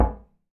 glass_2.ogg